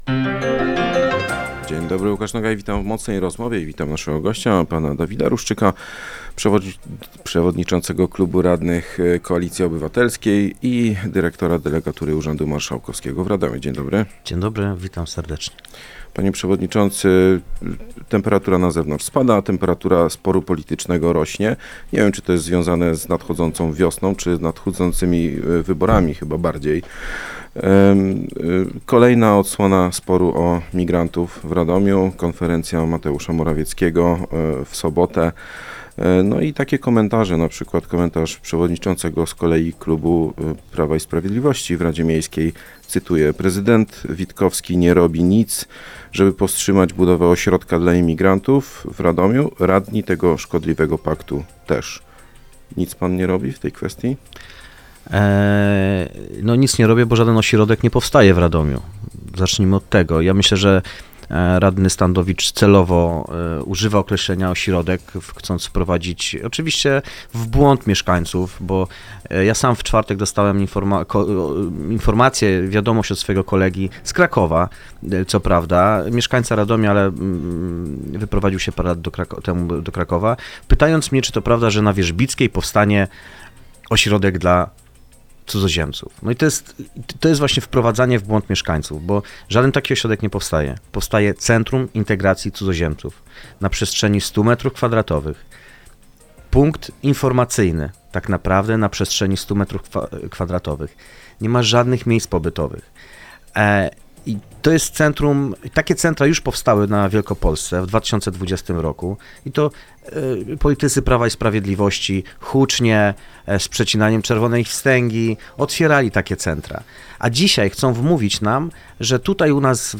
Przewodniczący radnych Koalicji Obywatelskiej Dawid Ruszczyk był gościem